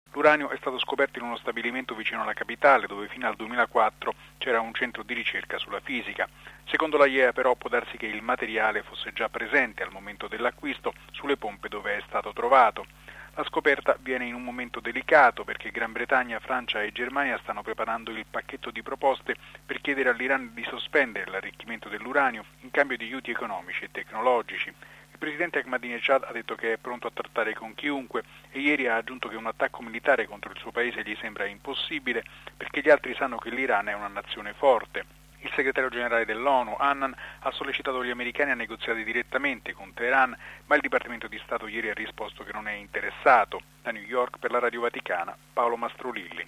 È l’allarme lanciato dagli ispettori dell'Agenzia internazionale per l’energia atomica che avrebbero trovato tracce di uranio arricchito, quasi pronto per la produzione di ordigni nucleari. Il servizio